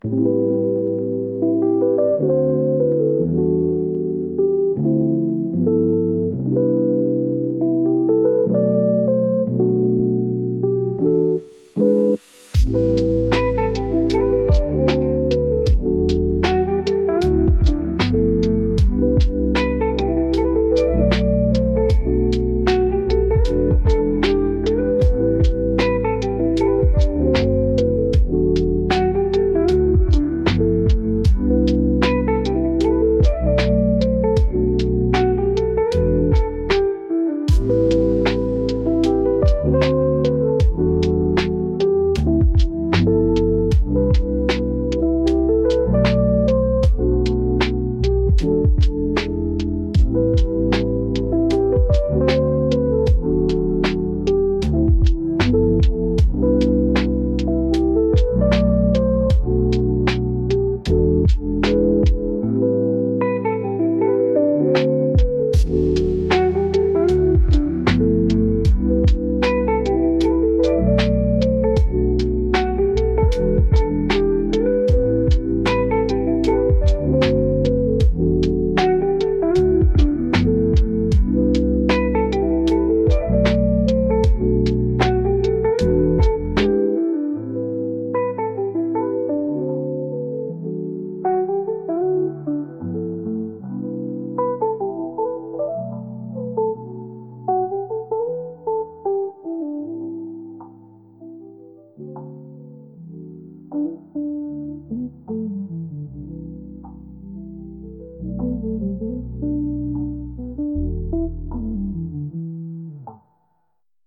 夜中にコーヒーを飲むようなゆったりとした落ち着きのエレキギター曲です。